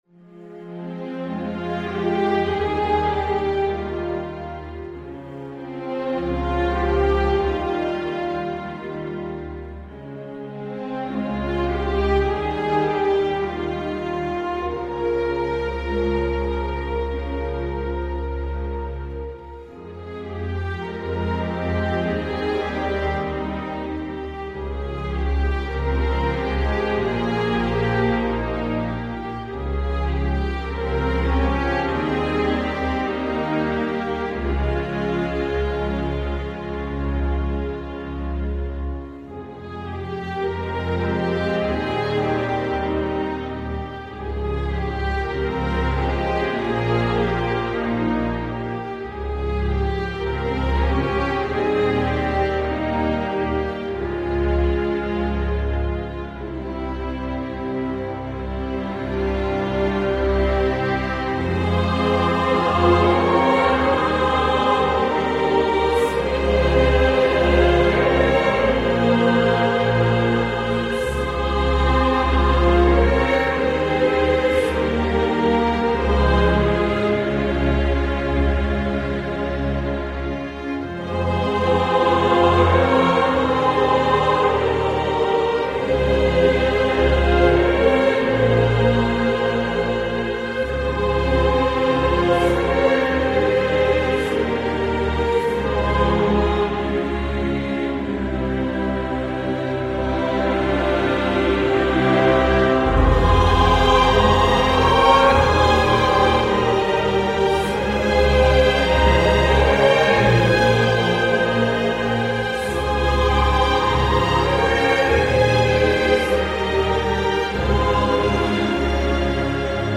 Lugubrement synthético-atmosphérique